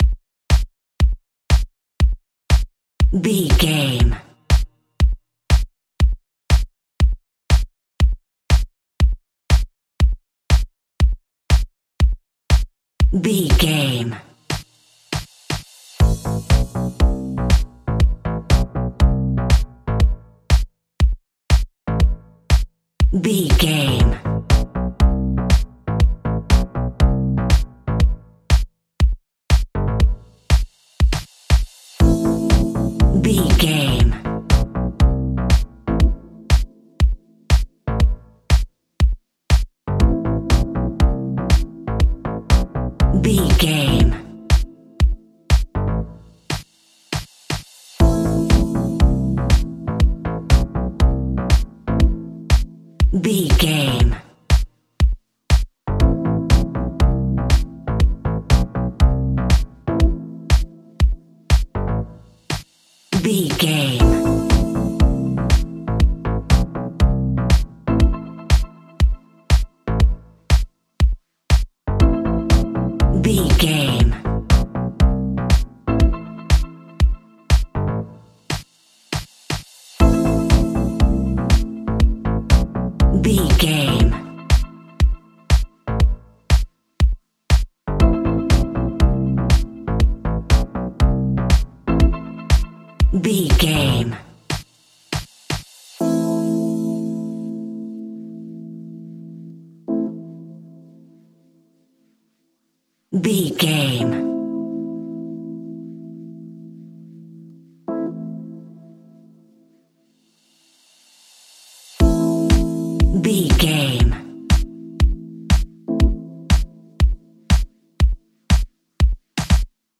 Aeolian/Minor
groovy
uplifting
driving
energetic
drums
bass guitar
synthesiser
electric piano
funky house
deep house
nu disco
upbeat